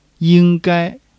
ying1--gai1.mp3